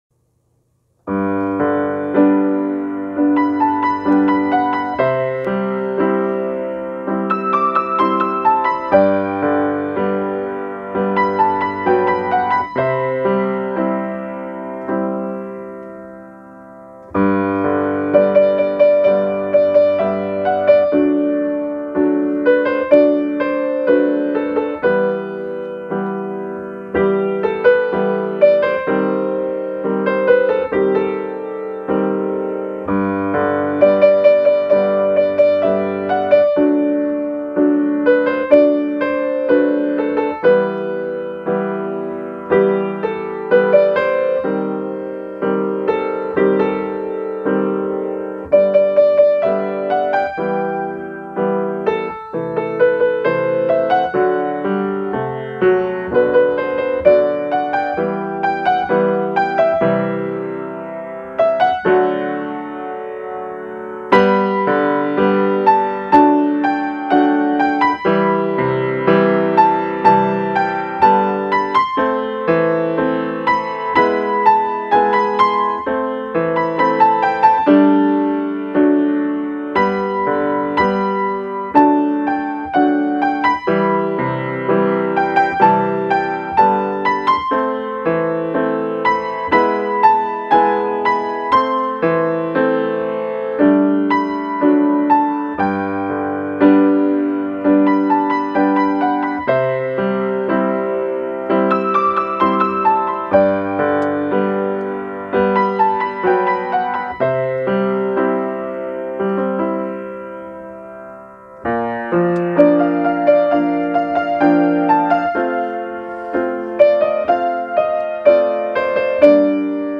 Piano Live